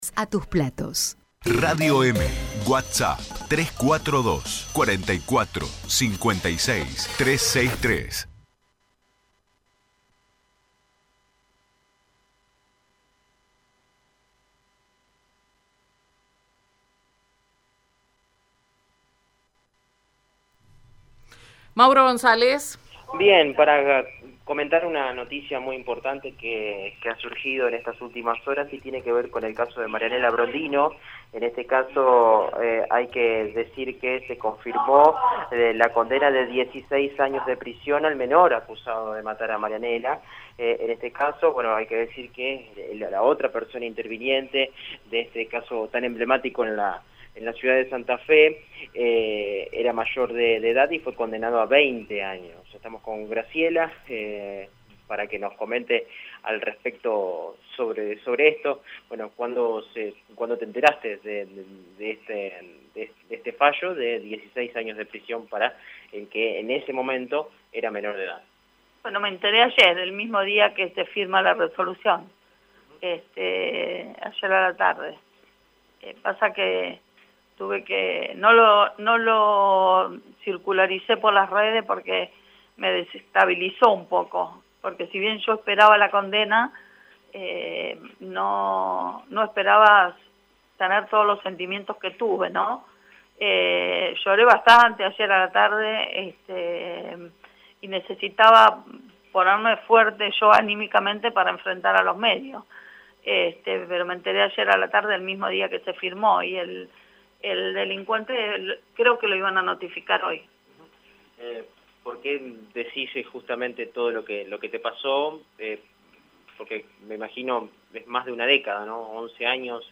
En dialogo con el móvil de Radio EME